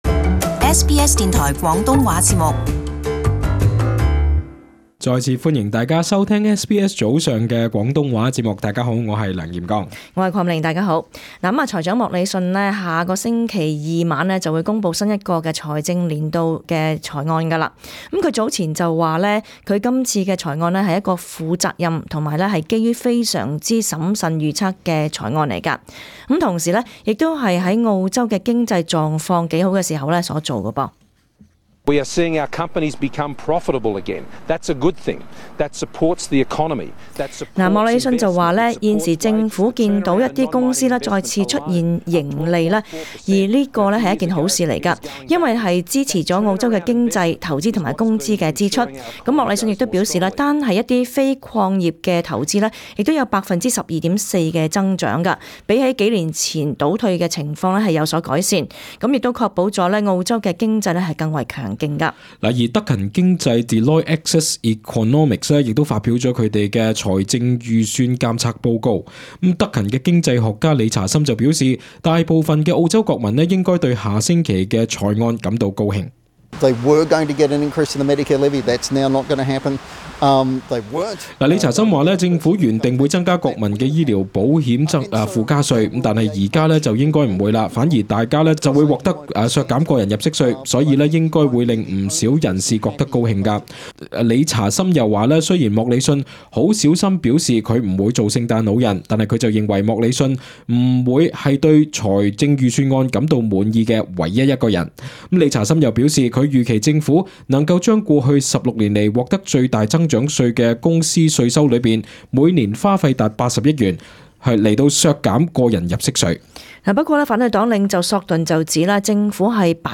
【時事報導】聯邦財案前瞻